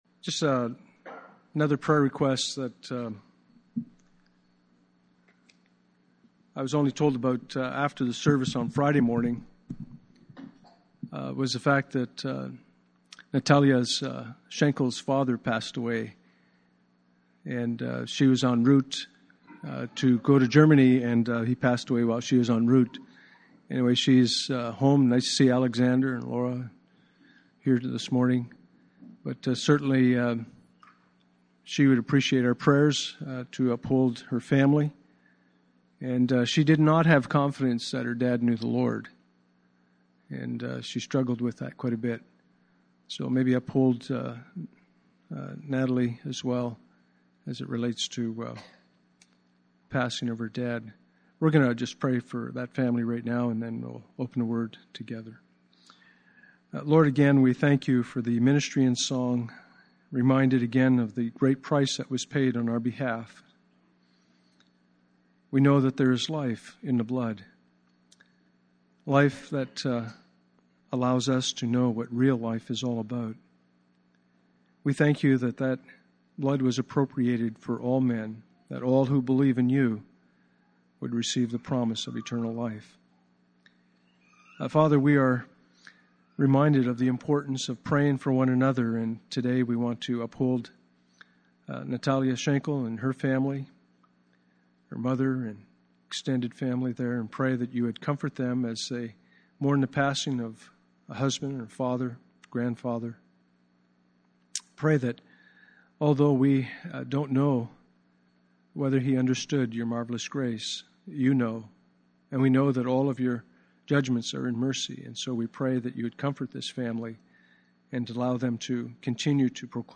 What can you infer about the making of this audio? Passage: John 20:1-18 Service Type: Sunday Morning